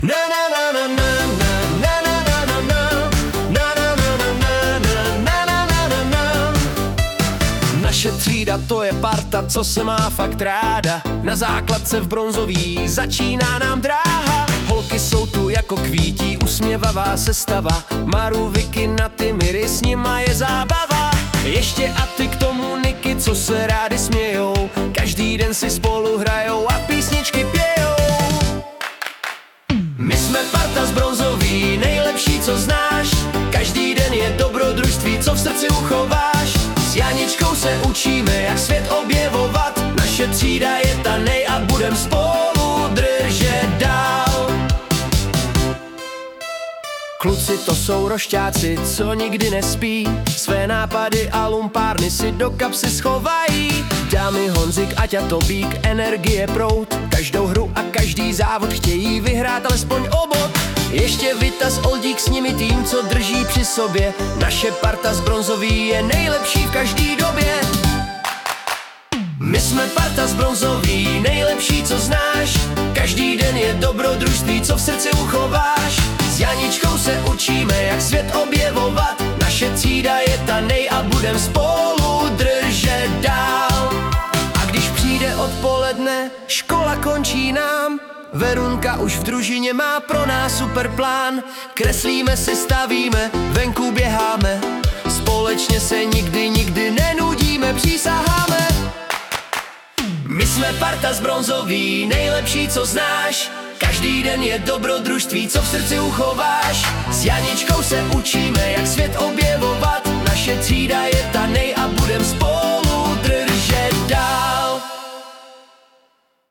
Píseň přípravné třídy 2025/2026